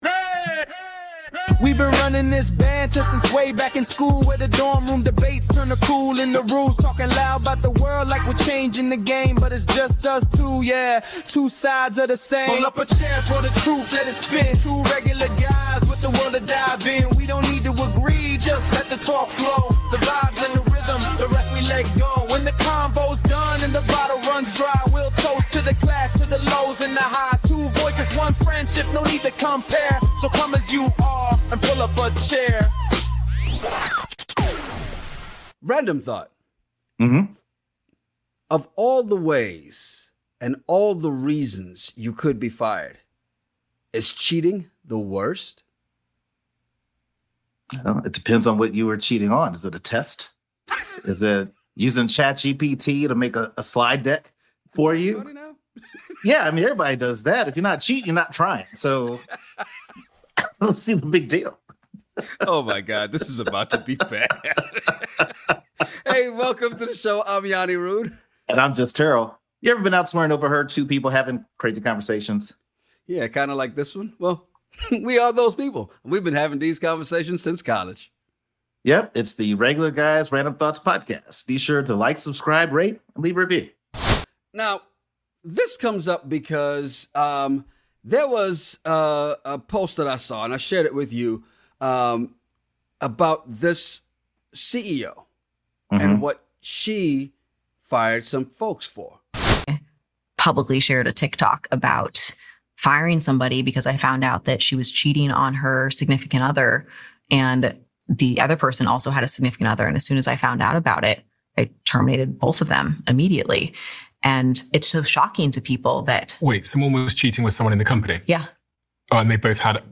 Well we are those guys and we have been having these conversations since college.